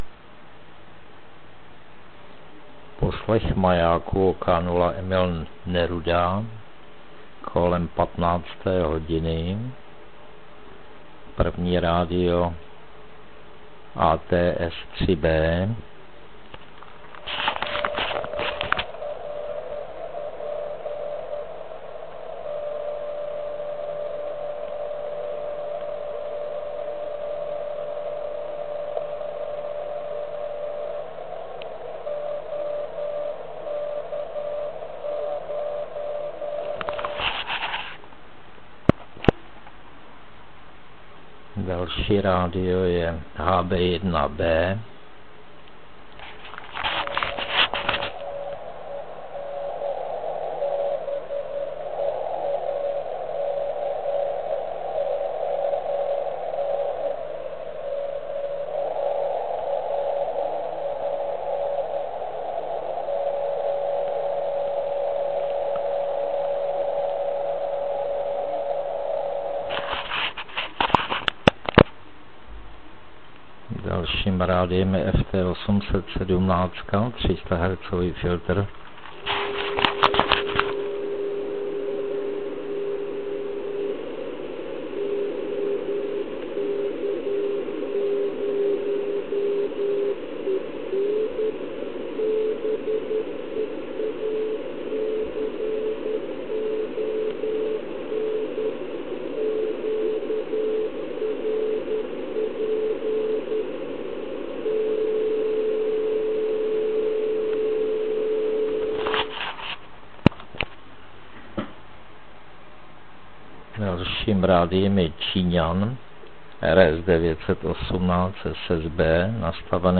Kolem 15. hodiny jsem naladil maják OK0EN (150mW). Podmínky ještě s velikým útlumem. Používal jsem stejná sluchátka, do kterých jsem při nahrávání strkal mp3 nahrávadlo.
Srovnával jsem to na stejné anténě.
Občas nám v nahrávce zachrchlala nějaká stanice SSB, ale snad to nevadí.